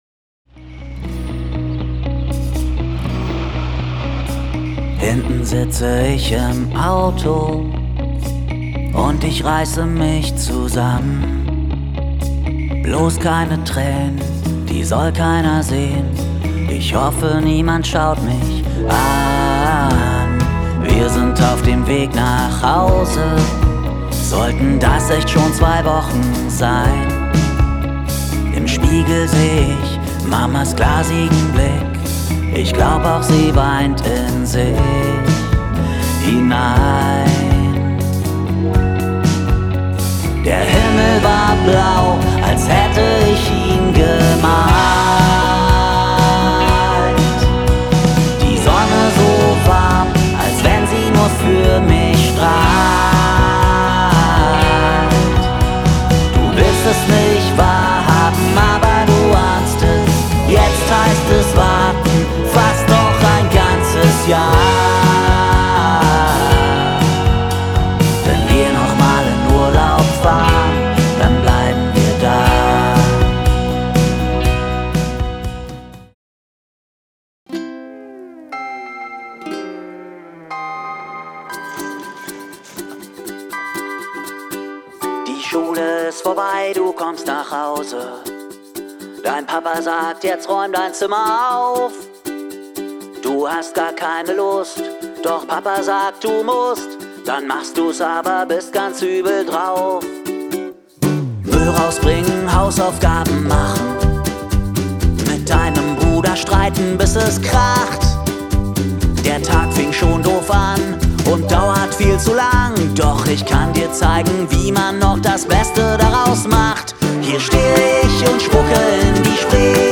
Neue Kinderlieder